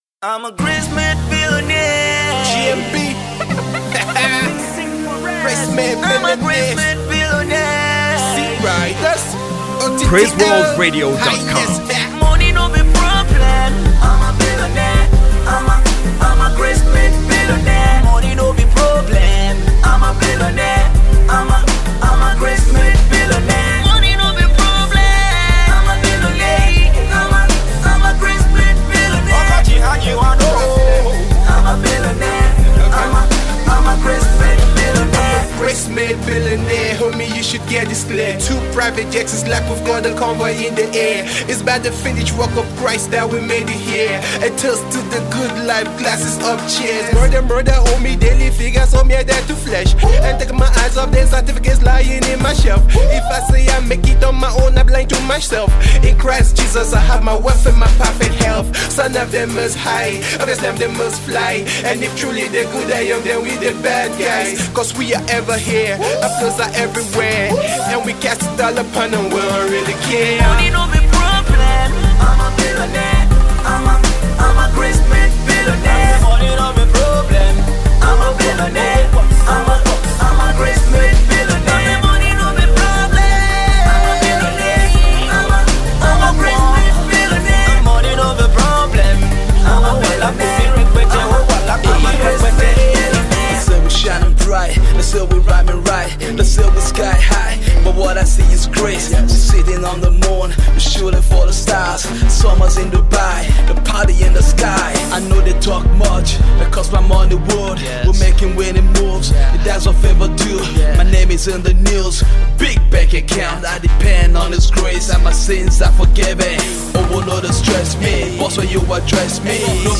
Gospel hip hop Duo